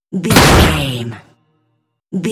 Dramatic hit metalic
Sound Effects
Atonal
heavy
intense
dark
aggressive